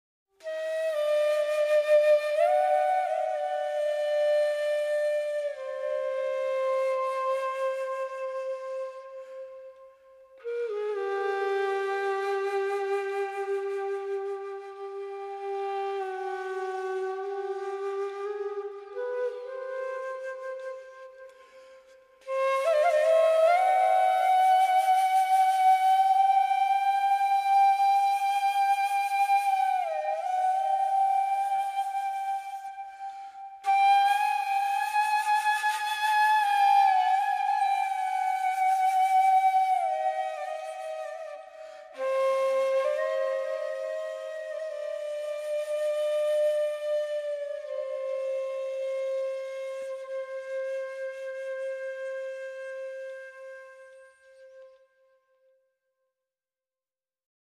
一幅音乐里的印度风情画卷
sit r（西塔尔琴）